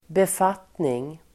Uttal: [bef'at:ning]